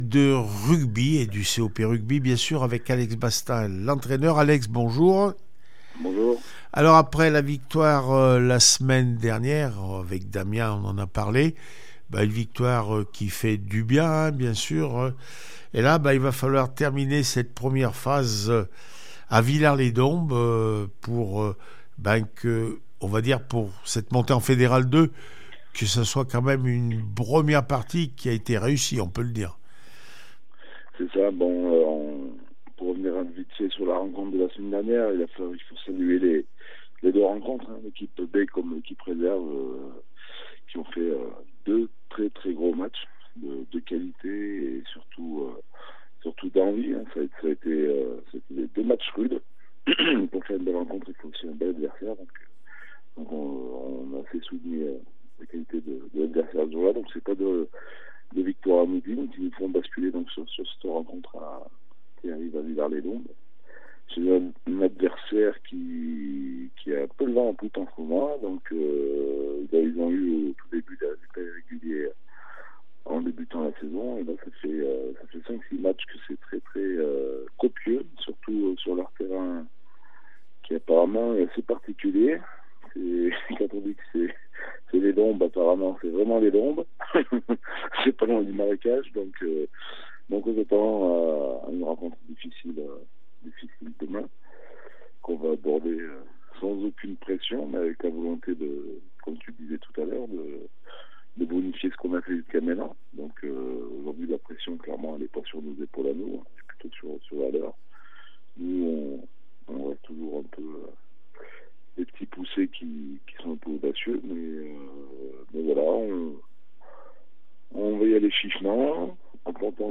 16 décembre 2023   1 - Sport, 1 - Vos interviews